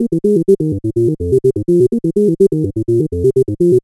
cch_bass_fuzz_125_Fm.wav